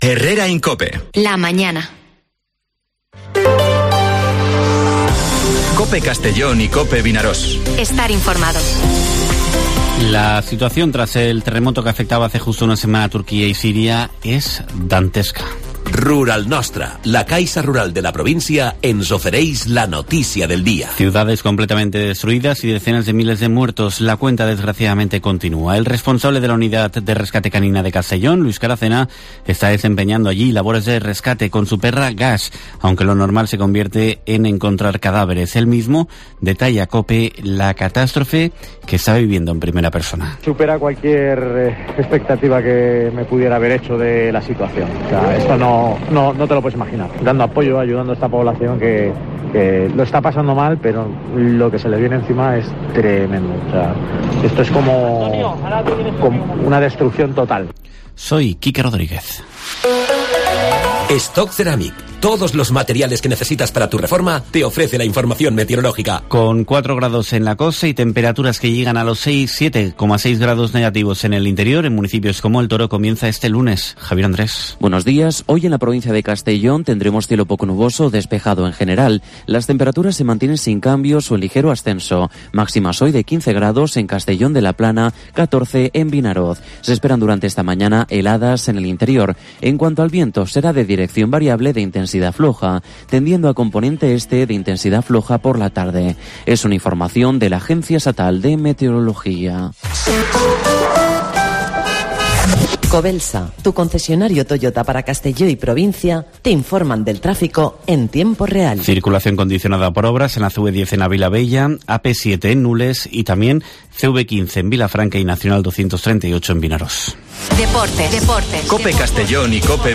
Informativo Herrera en COPE en la provincia de Castellón (13/02/2023)